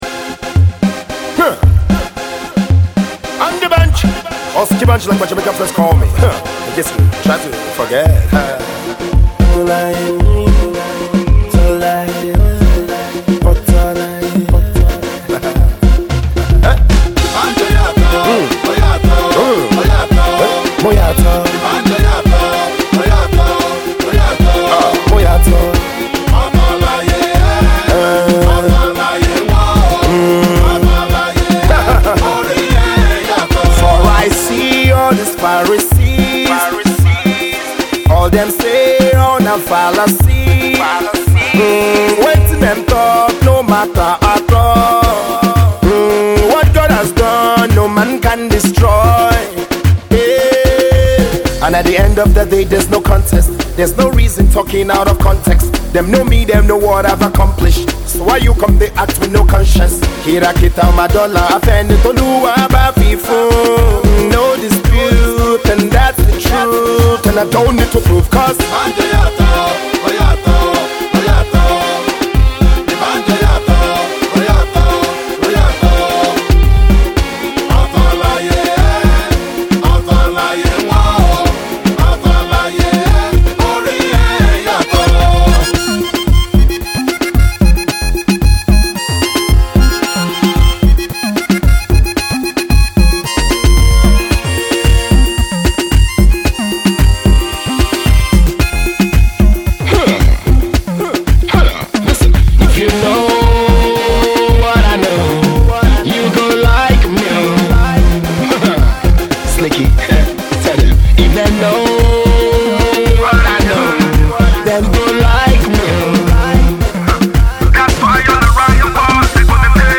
… the harmonica’s back too.